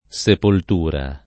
vai all'elenco alfabetico delle voci ingrandisci il carattere 100% rimpicciolisci il carattere stampa invia tramite posta elettronica codividi su Facebook sepoltura [ S epolt 2 ra ] (ant. sepultura [ S epult 2 ra ]) s. f.